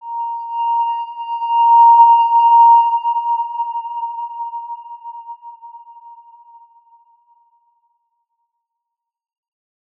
X_Windwistle-A#4-mf.wav